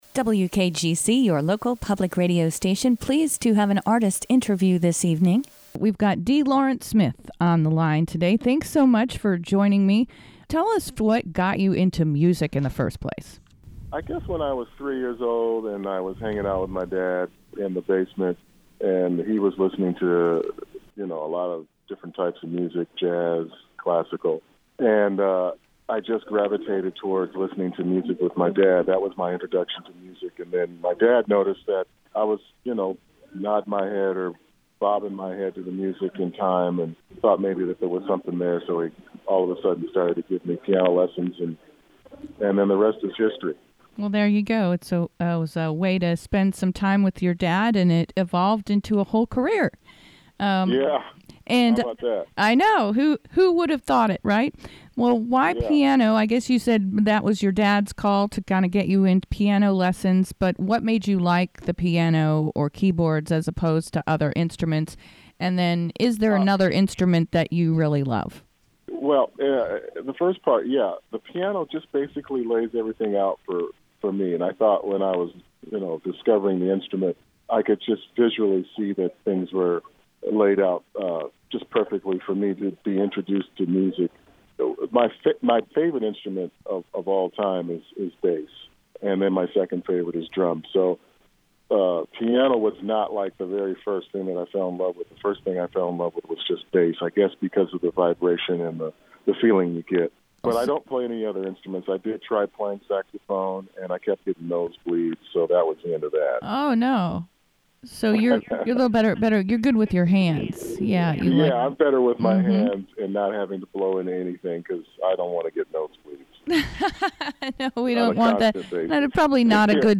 The interview aired on WKGC’s locally originated Jazz variety program, “Jazz ‘Til Tomorrow.”